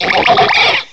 cry_not_frogadier.aif